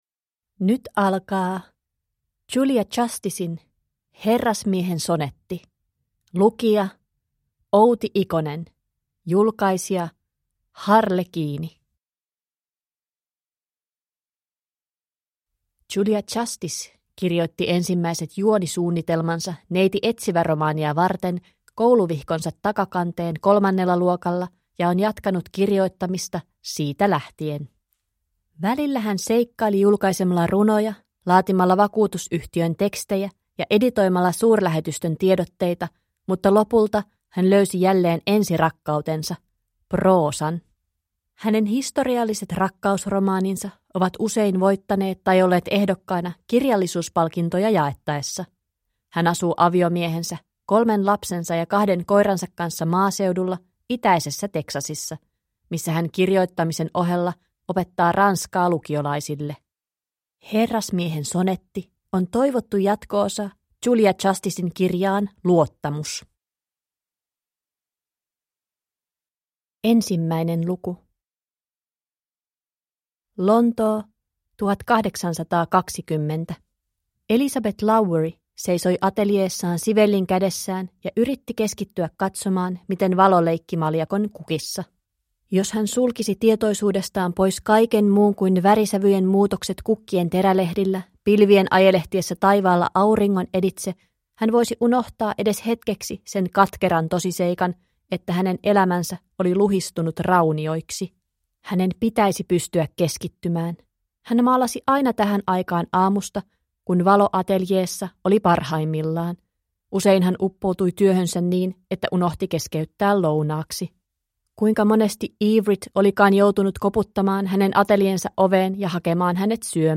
Herrasmiehen sonetti (ljudbok) av Julia Justiss